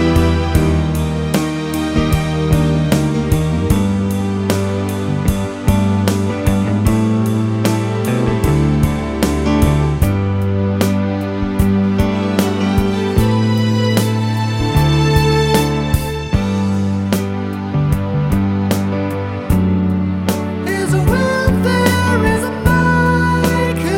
Minus Harmonica Pop (1960s) 4:33 Buy £1.50